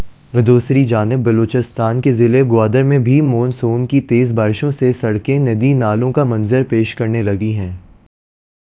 Spoofed_TTS/Speaker_14/265.wav · CSALT/deepfake_detection_dataset_urdu at main